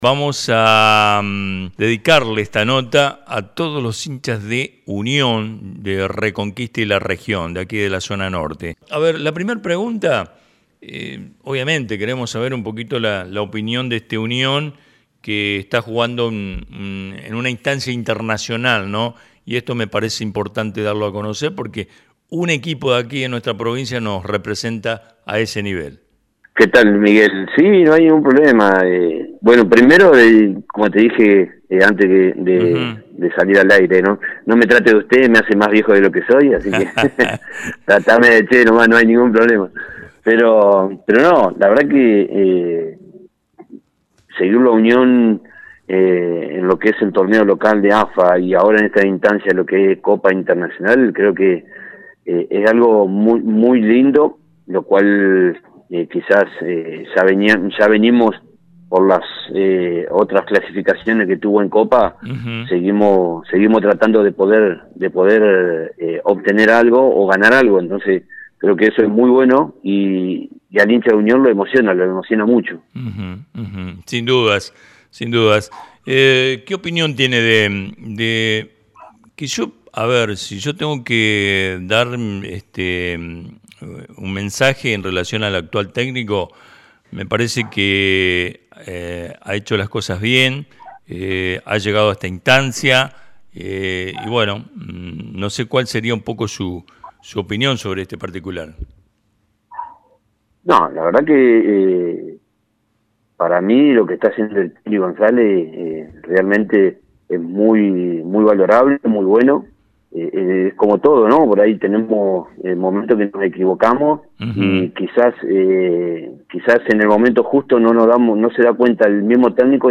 respondió entre risas.